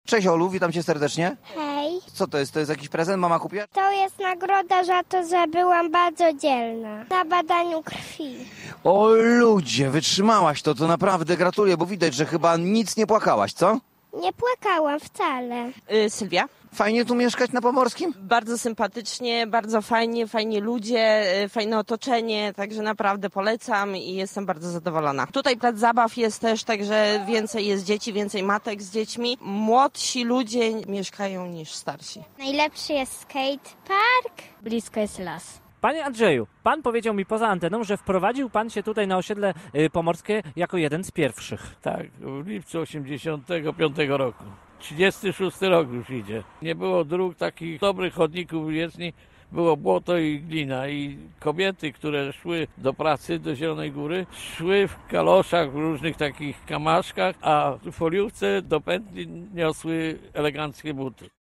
Osiedle Pomorskie odwiedziła dziś ekipa Dobrego Początku Dnia w Radiu Zielona Góra.
rozmawiali z mieszkańcami osiedla